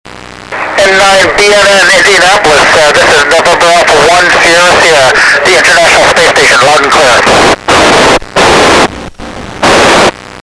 My first contact with ISS was very short, just an acknowledgement that Doug Wheelock received my call.